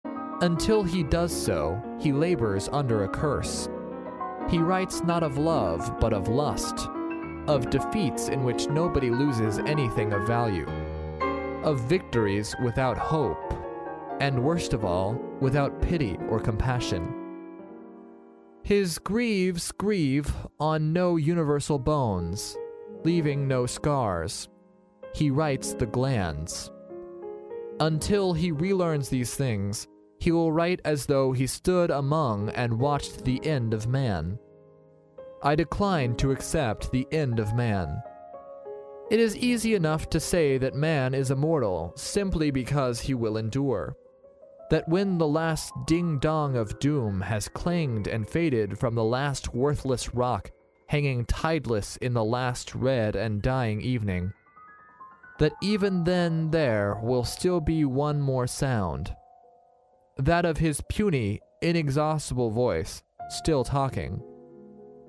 在线英语听力室历史英雄名人演讲 第6期:作家的责任(2)的听力文件下载, 《历史英雄名人演讲》栏目收录了国家领袖、政治人物、商界精英和作家记者艺人在重大场合的演讲，展现了伟人、精英的睿智。